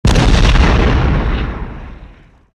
Nuclear Explosion Sound Effect Free Download
Nuclear Explosion